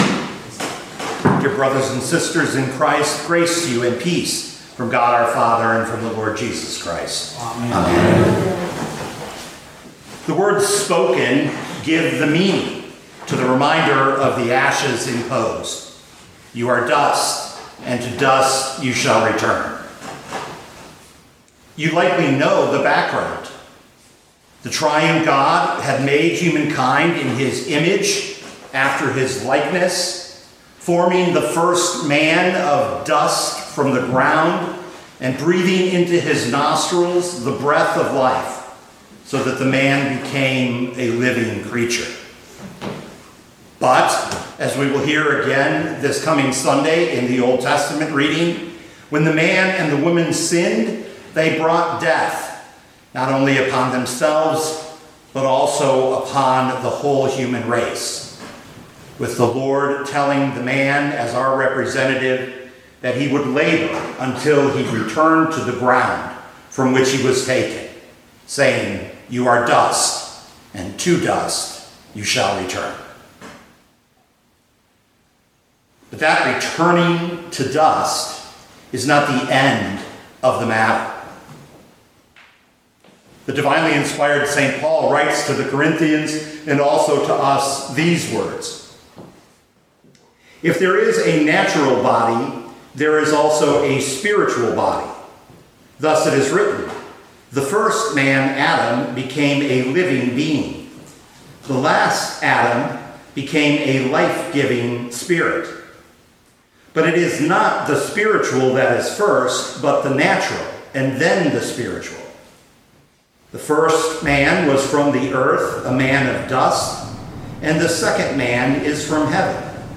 2026 1 Corinthians 15:44b-49 Listen to the sermon with the player below, or, download the audio.